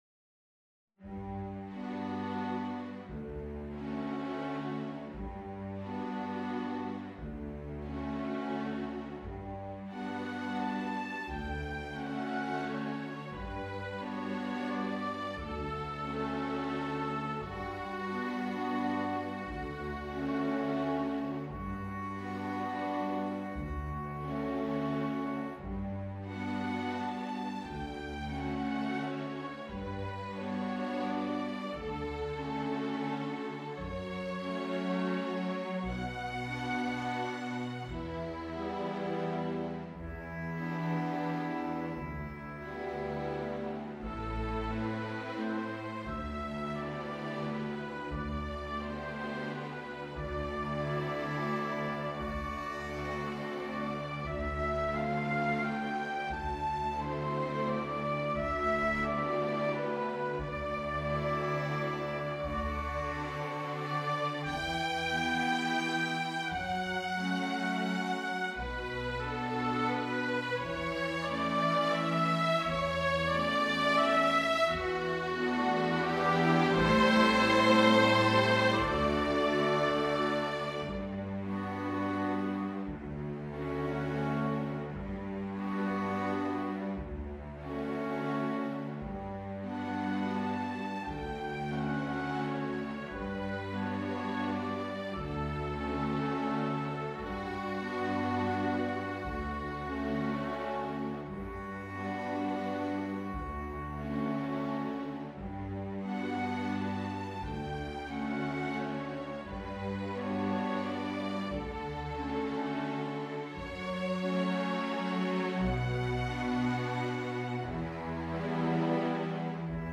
Strings (Violin 1, Violin 2, Viola or Violin 3, Cello, Bass)
hauntingly beautiful
is here arranged for orchestra.
Orchestra